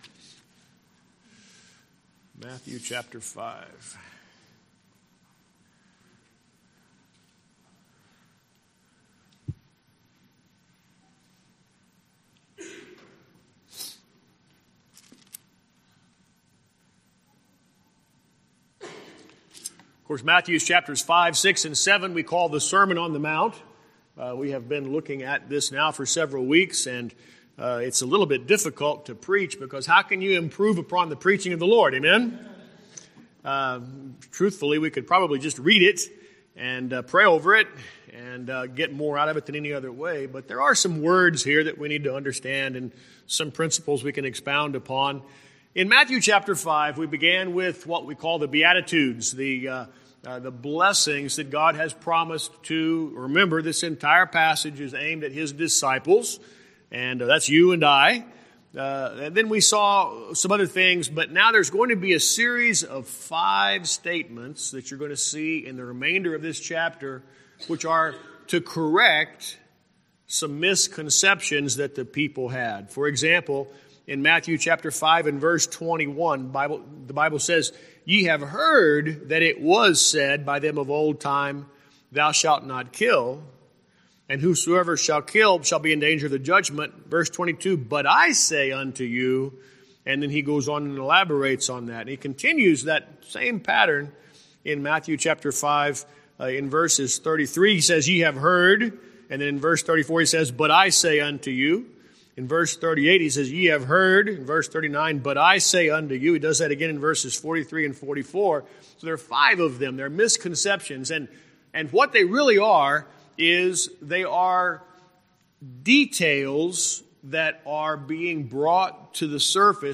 Guest Speaker